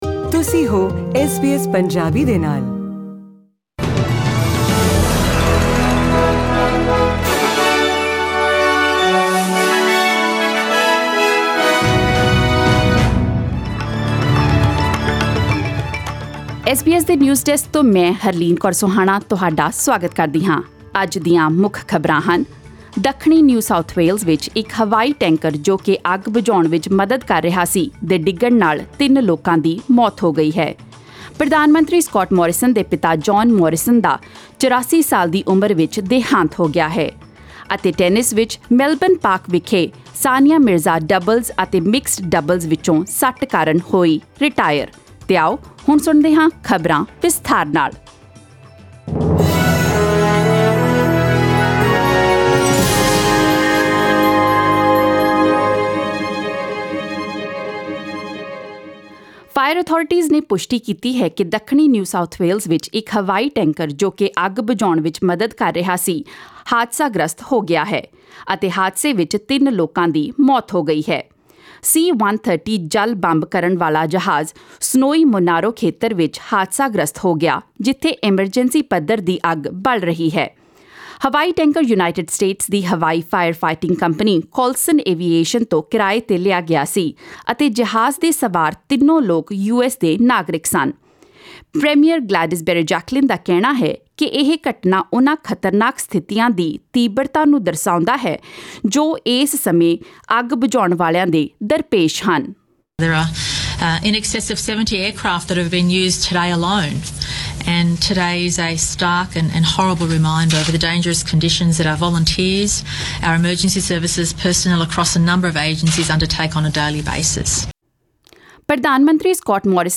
Presenting the major news stories of today with updates on sports, currency exchange rates and the weather forecast for tomorrow.